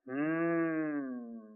描述：Thinking malePlease credit.
标签： man voice sounds human vocal beatbox deep thinking male
声道立体声